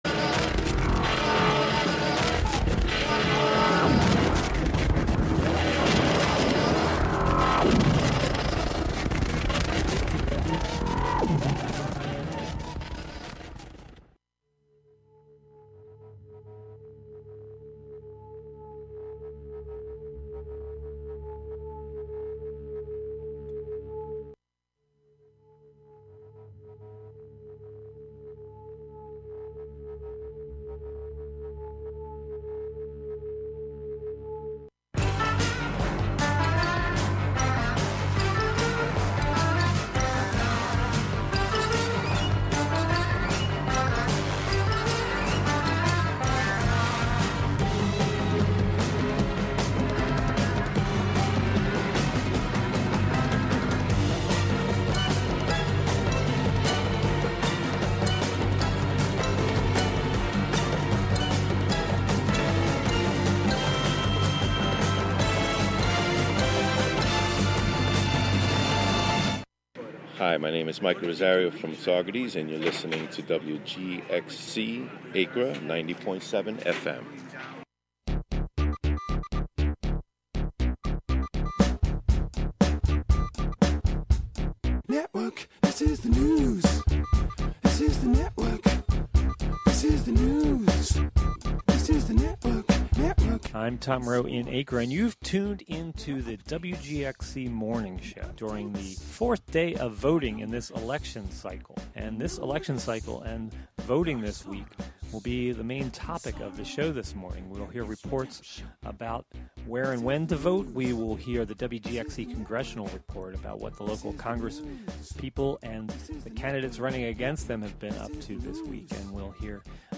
Tune in for this week's WGXC Congressional Report, tracking the votes, statements, positions, and campaigns of the representatives and candidates for the 18th, 19th, 20th, and 21st Congressional seats in New York. The "WGXC Morning Show" is a radio magazine show featuring local news, interviews with community leaders and personalities, reports on cultural issues, a rundown of public meetings and local and regional events, with weather updates, and more about and for the community, made mostly through volunteers in the community through WGXC.
Sometimes national, state, or local press conferences, meetings, or events are also broadcast live here.